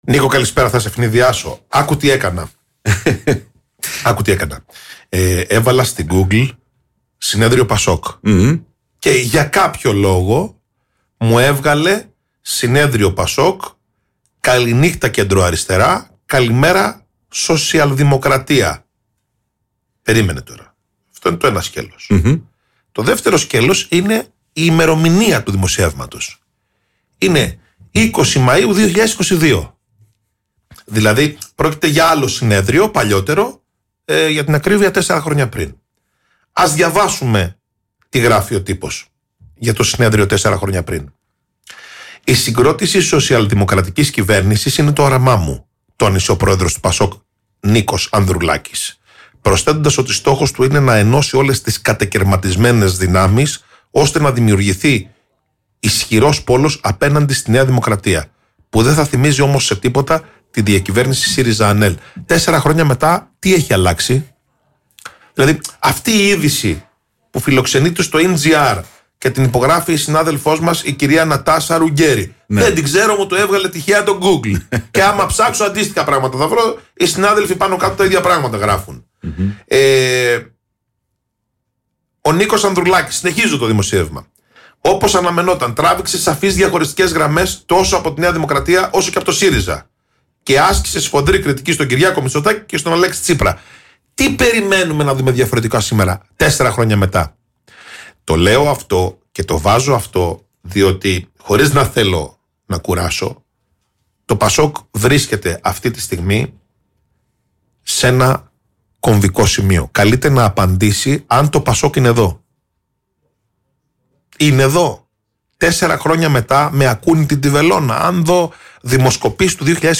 Για τις εξελίξεις στο ΠΑΣΟΚ μίλησε στο κεντρικό δελτίο ειδήσεων του Politica 89, 8